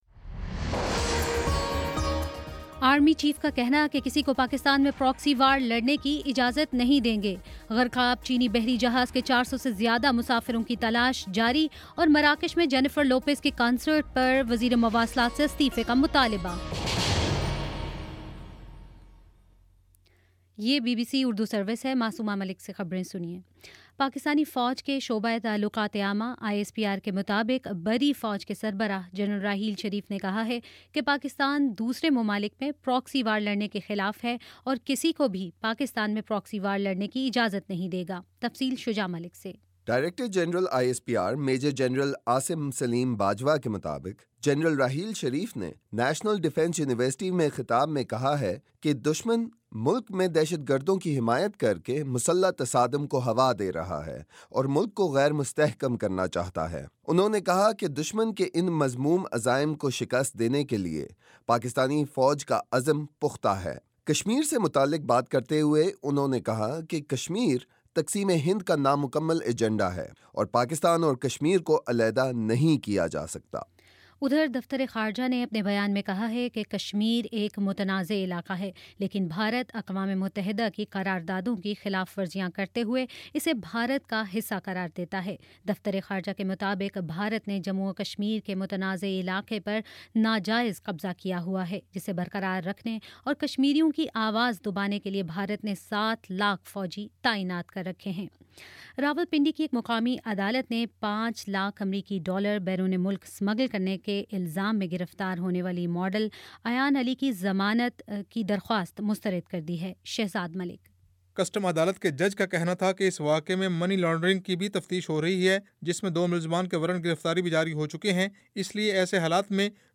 جون 3: شام پانچ بجے کا نیوز بُلیٹن